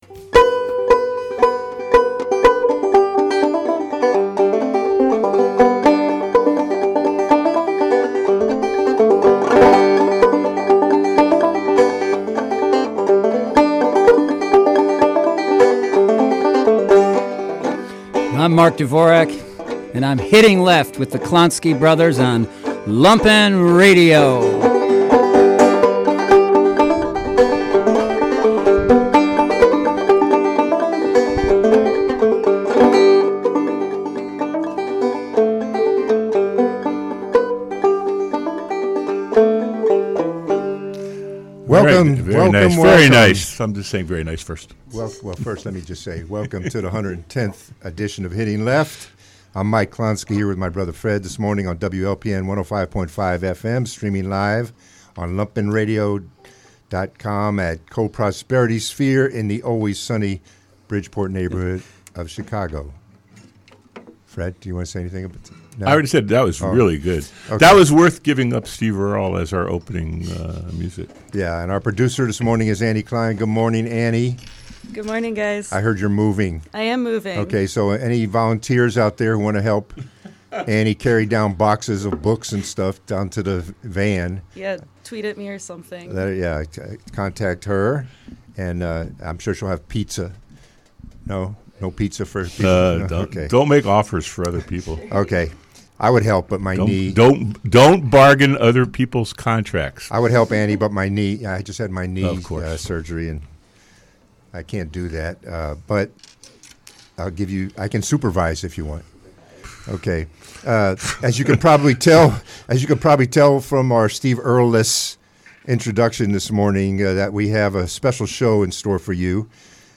Listen to the entire 105.5 fm conversation here .